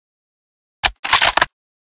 bolt_layer.ogg